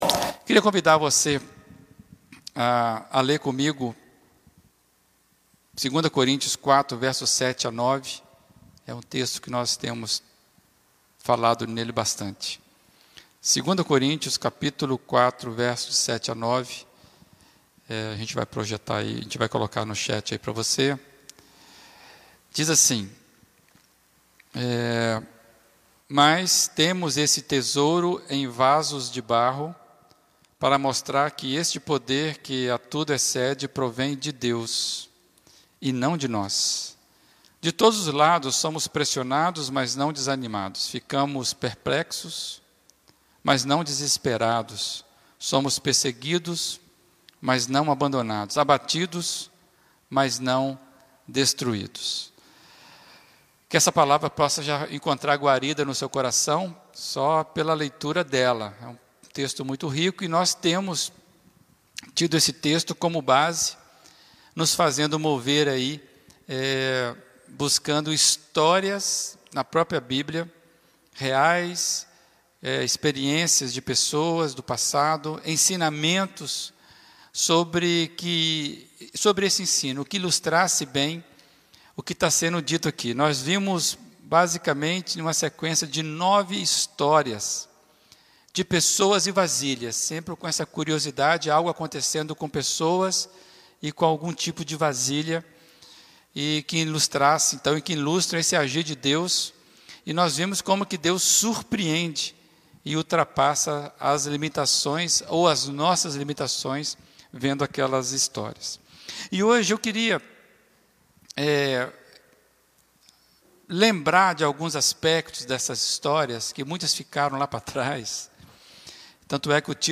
Mensagem
na Primeira Igreja Batista de Brusque